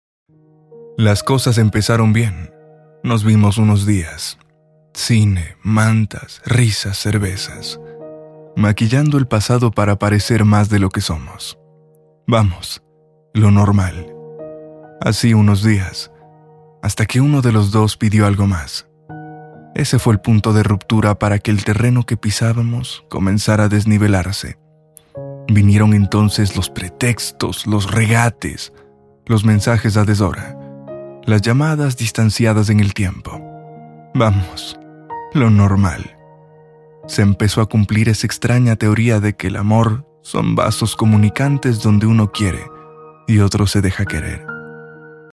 Échantillons de voix natifs
Narration